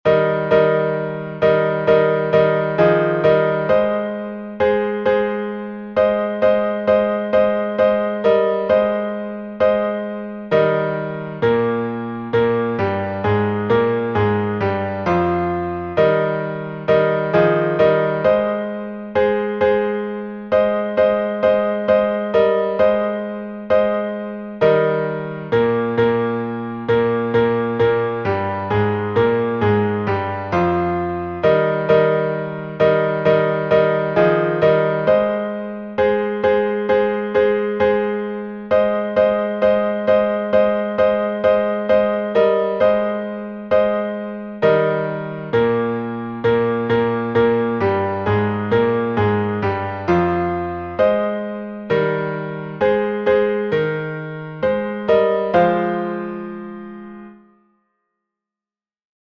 Киевский распев, глас 5